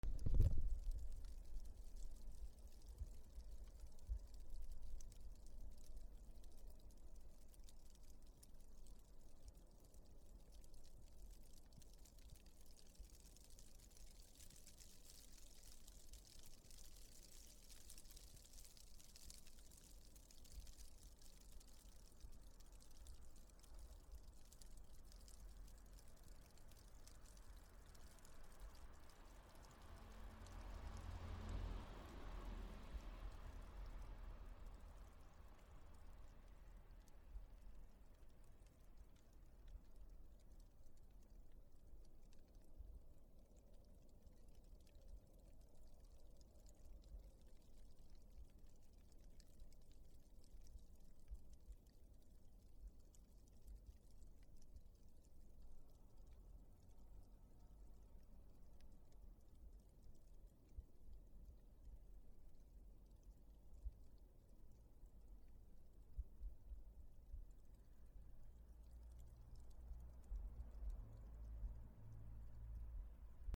雪が木の葉に当たる音
/ M｜他分類 / L35 ｜雪・氷 /
MKH416